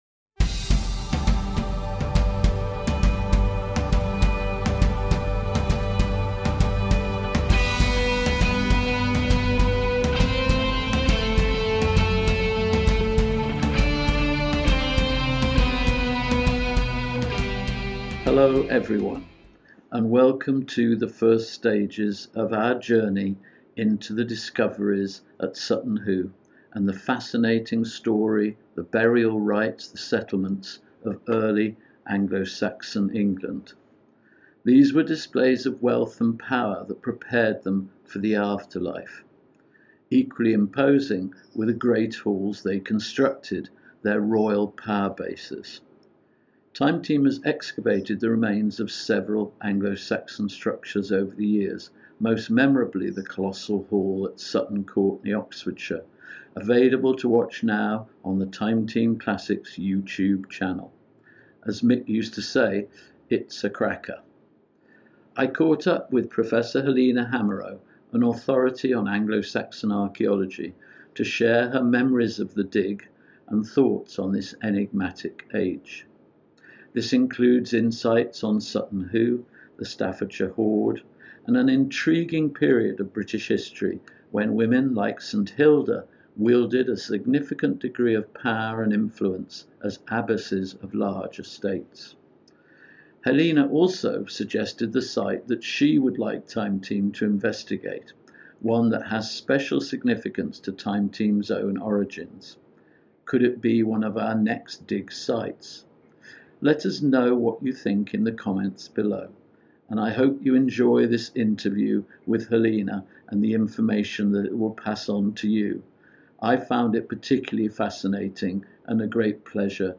The original video interview is available to watch on the Time Team Official YouTube Channel.